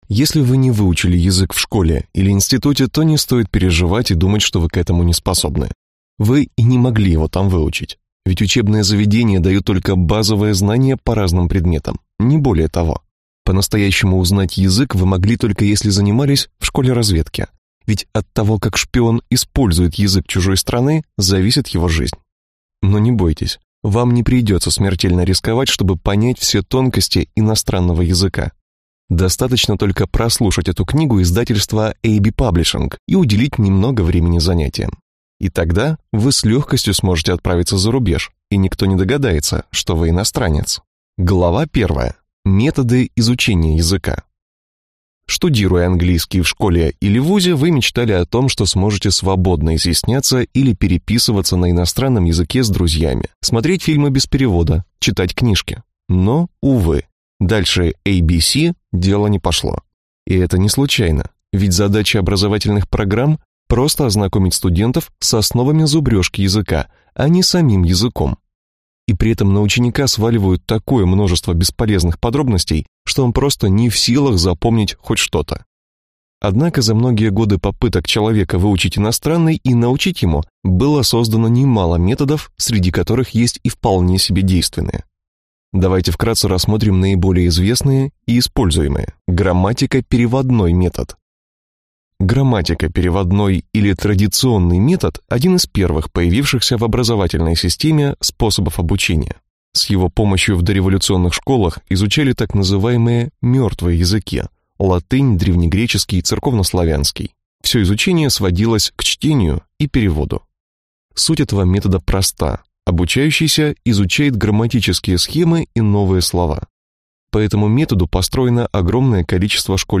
Аудиокнига Спецкурс английского. Суперметодика секретных служб | Библиотека аудиокниг